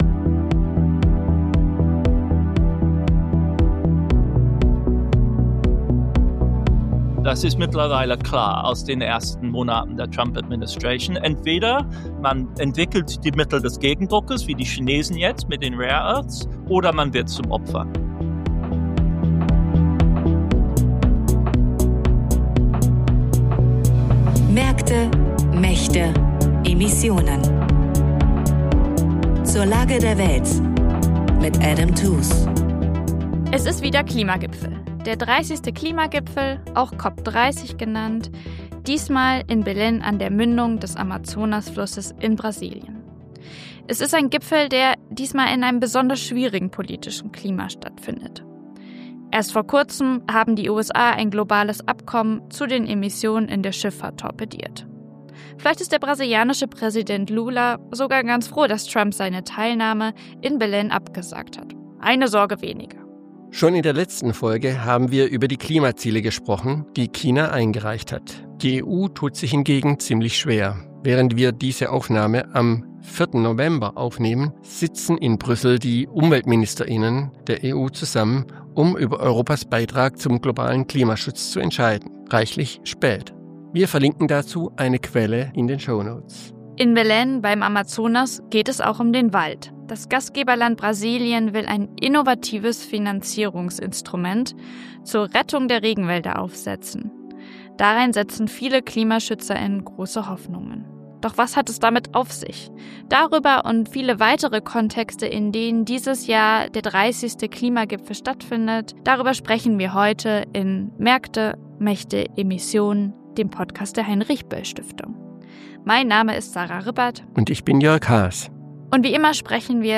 Mit dem prominenten Wirtschaftshistoriker Adam Tooze besprechen wir einmal im Monat die Krisen unserer Zeit an den Schnittstellen von Geopolitik, globaler Ökonomie und Klimakrise: Wie können Märkte, Mächte und die Klimakrise zusammen gedacht werden?